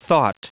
Prononciation du mot thought en anglais (fichier audio)
Prononciation du mot : thought